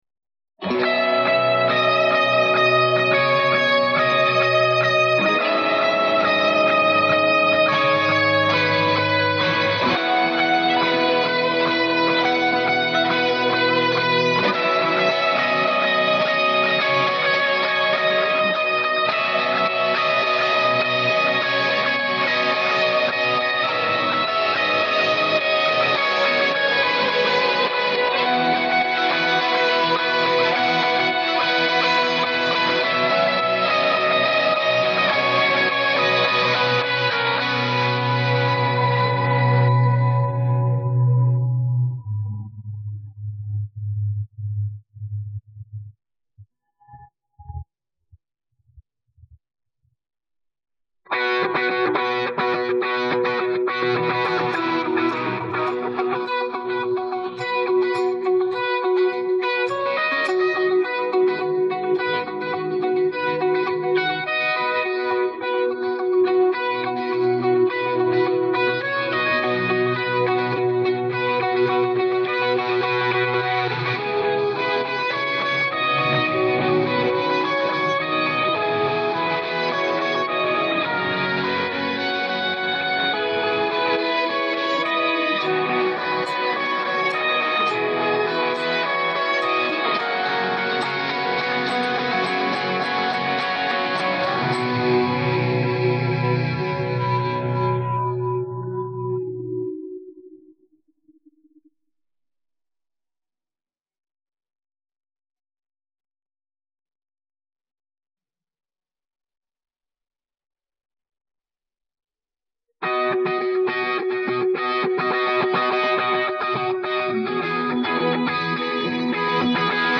Download Guitar Track
Key of B | BPM 105